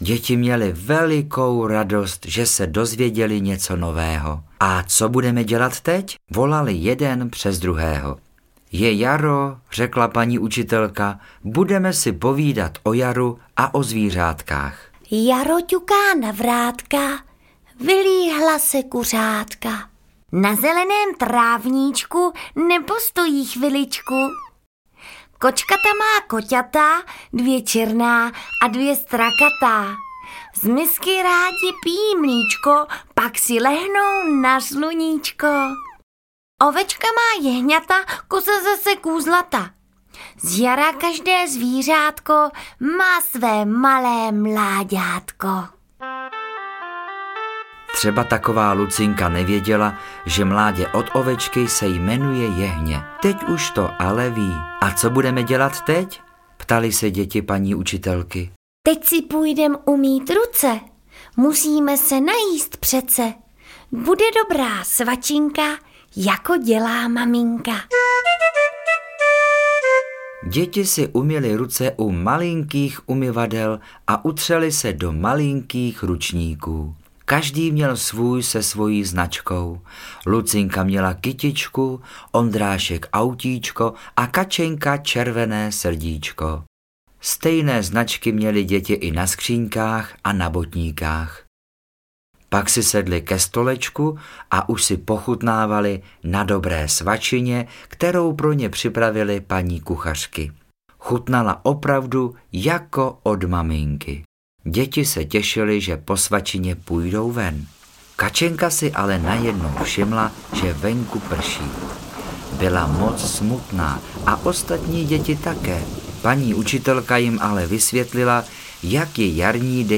Ukázka z knihy
Příběh malé Kačenky, která jde poprvé do školky, popisuje svět, který vidí kolem sebe a rozvíjí fantazii. Děti tak poznají hudební nástroje, přírodu a denní pořádek ve školce. Povídání je střídáno krátkými písničkami.